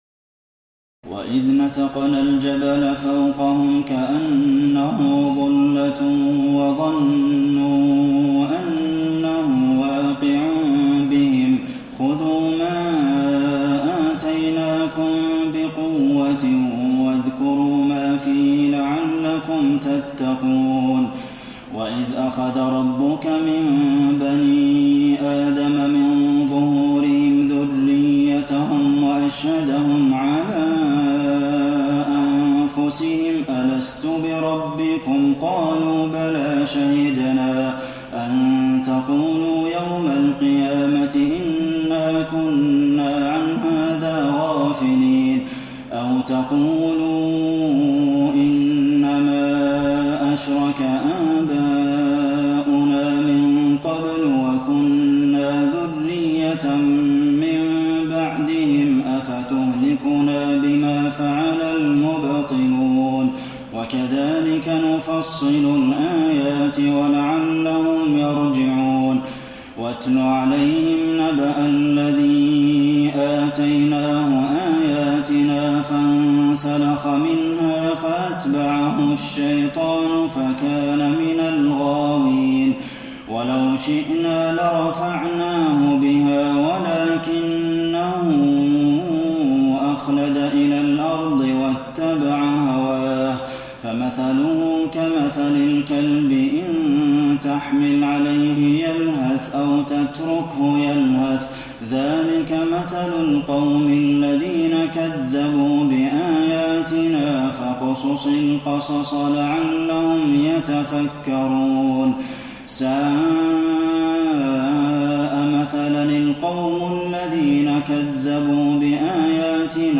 تراويح الليلة التاسعة رمضان 1432هـ من سورتي الأعراف (171-206) والأنفال (1-40) Taraweeh 9 st night Ramadan 1432H from Surah Al-A’raf and Al-Anfal > تراويح الحرم النبوي عام 1432 🕌 > التراويح - تلاوات الحرمين